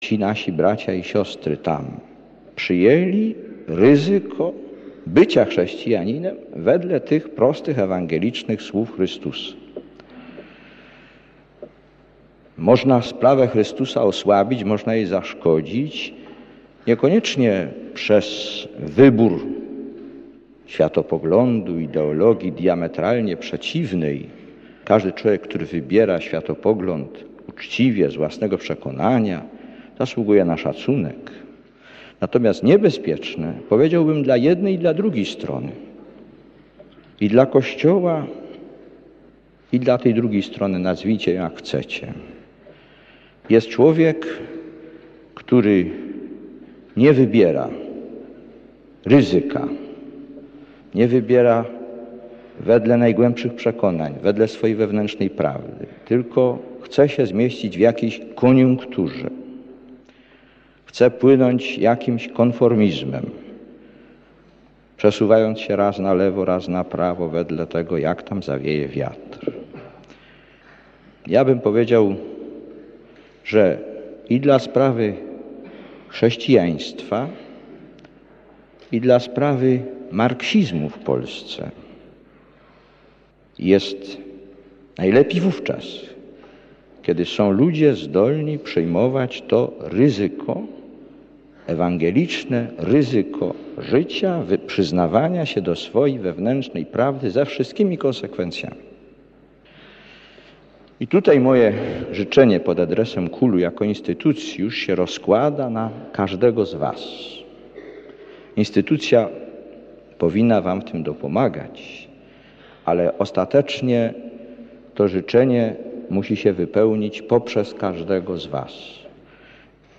Mówi do nas Św. Jan Paweł II